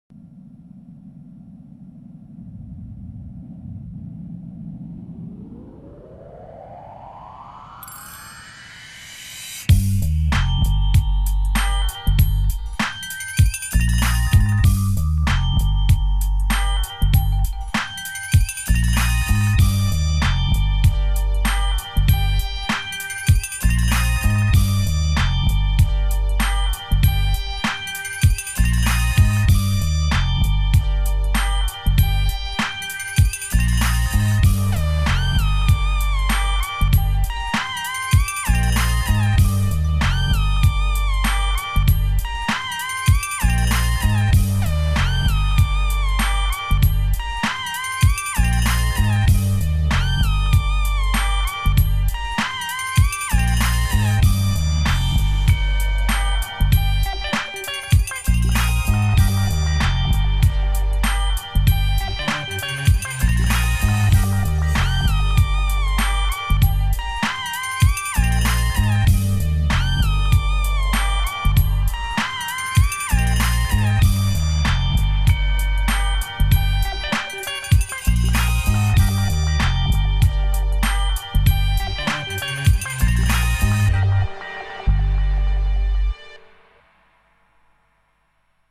Саундтрек к игре: